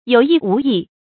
有意無意 注音： ㄧㄡˇ ㄧˋ ㄨˊ ㄧˋ 讀音讀法： 意思解釋： 又像是有意的，又像是無意的。